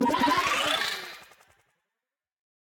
teleporting sound
teleport.ogg